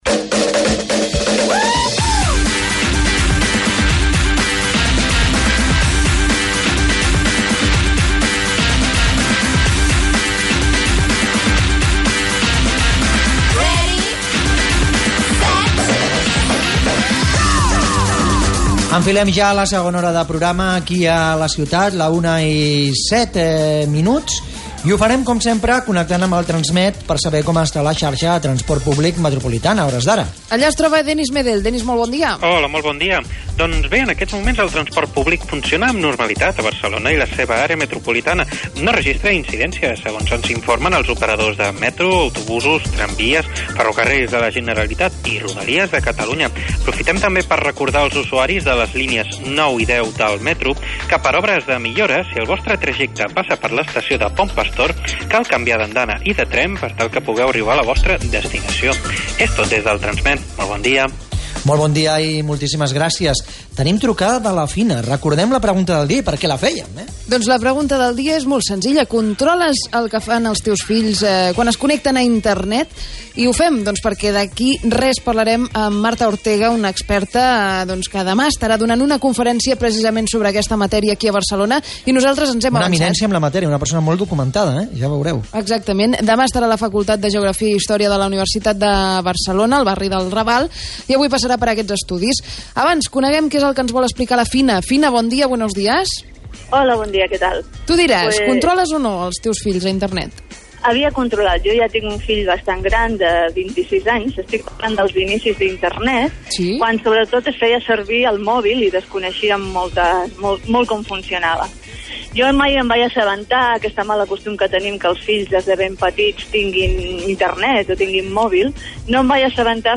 RAdio: Podcast Programa «La Ciutat» de Onda Cero Catalunya. Hablando de cómo preparar las fiestas navideñas. 1 Diciembre 2014